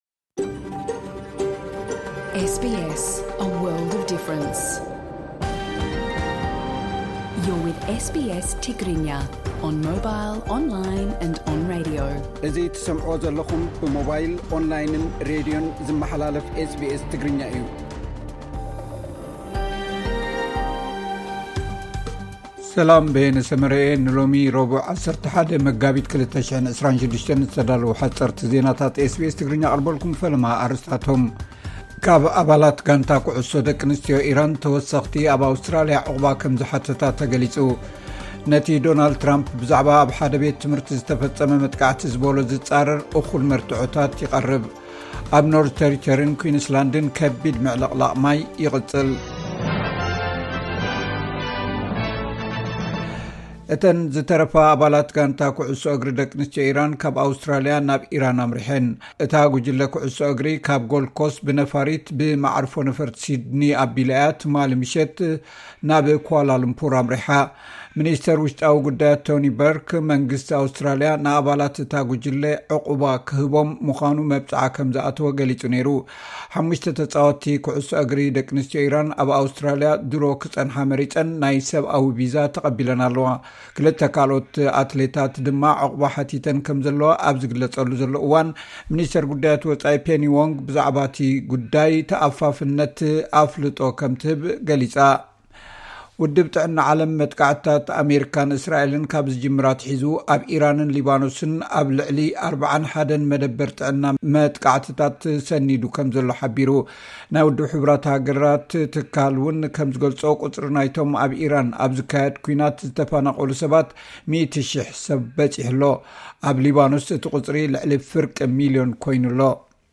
ሓጸርቲ ዜናታት SBS ትግርኛ (11 መጋቢት 2026)